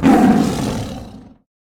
general / combat / creatures / tiger / he / turn1.ogg